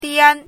diān
拼音： diān
注音： ㄉㄧㄢ
dian1.mp3